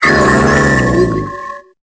Cri d'Archéodong dans Pokémon Épée et Bouclier.